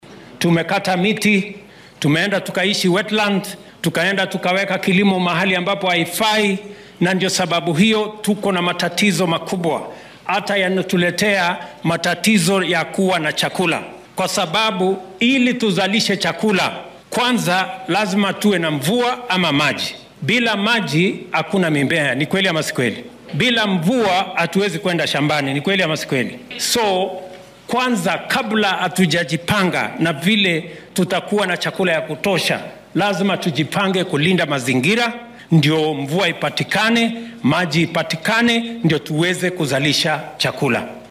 Madaxweynaha dalka William Ruto ayaa arrimahan ka hadlay xilli uu shalay magaalada Makindu ee ismaamulka Makueni ka daahfuray barnaamij qaran oo geedo lagu beerayay.
William-Ruto-3.mp3